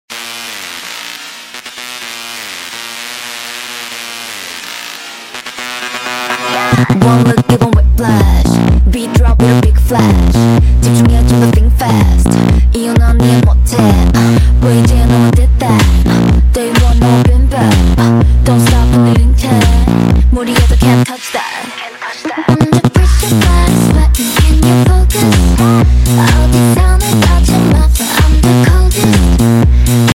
Beat drop with a big flash